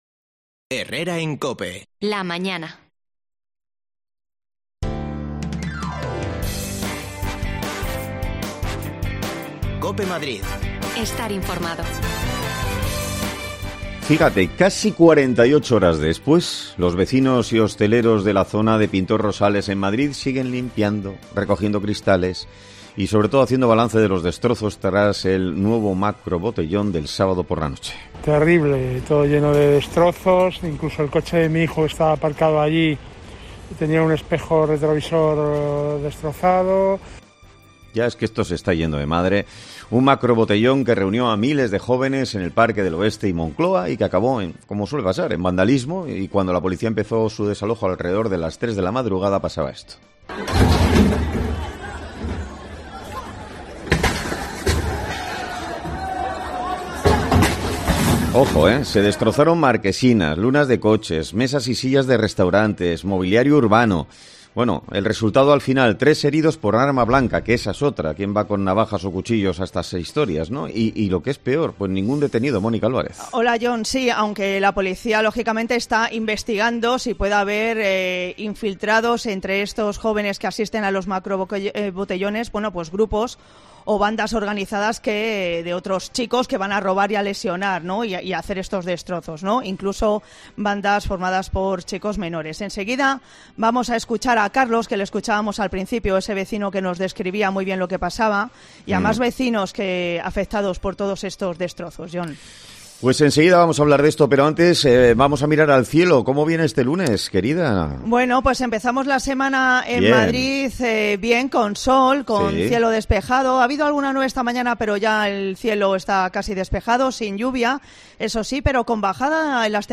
Hablamos con los vecinos de la zona